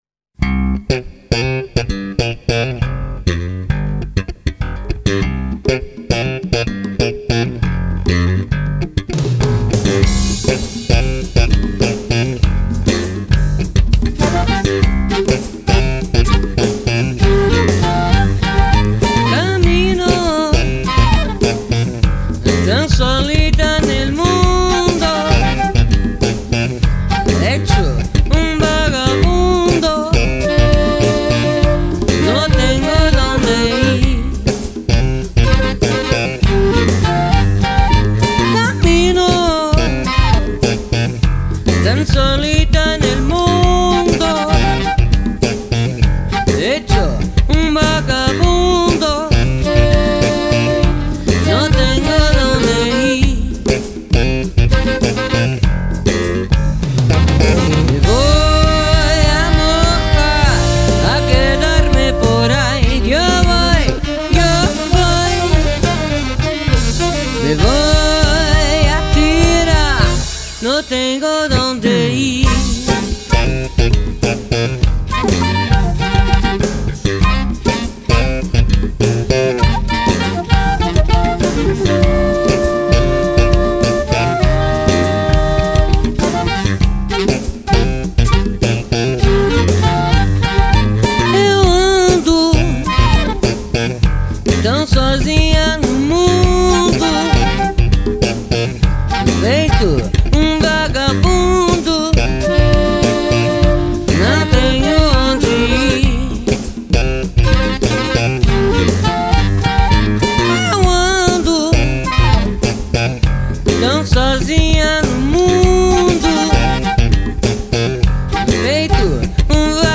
o rock...